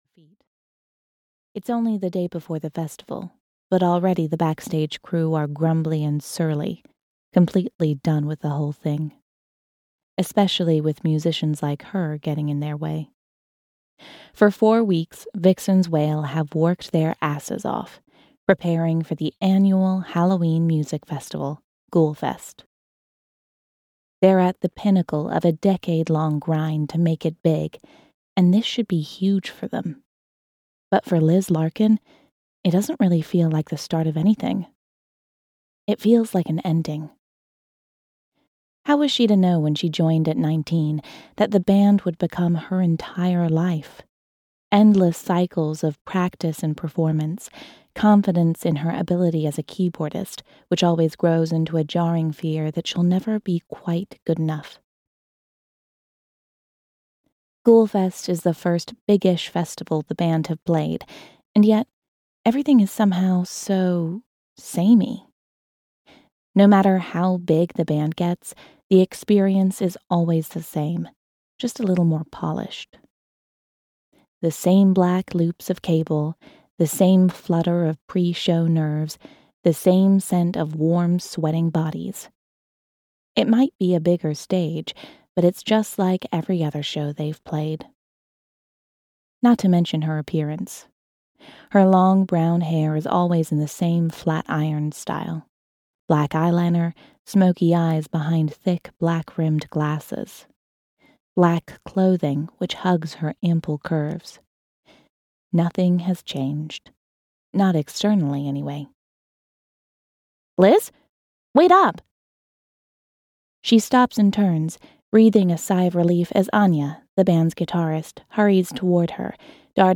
Amped (EN) audiokniha
Ukázka z knihy